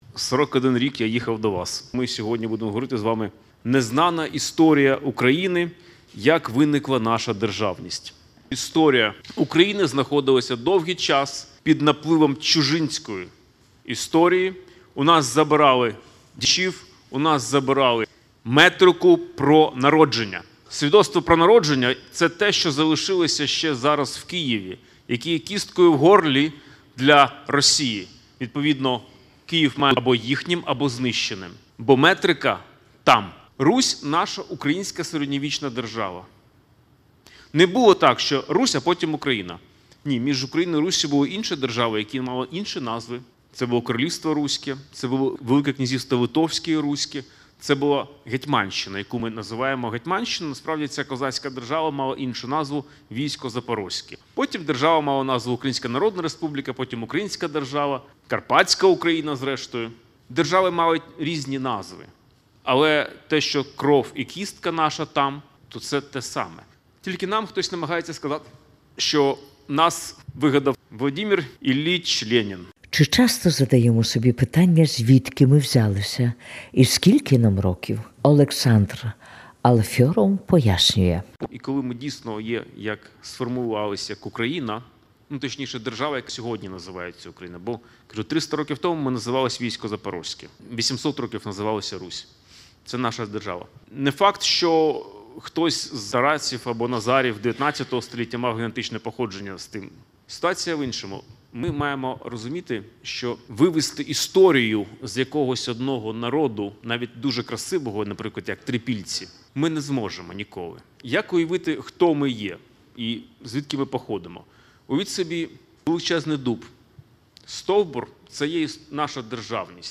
Кілька днів перемиляни маи змогу послухати його виклад про державу і державність.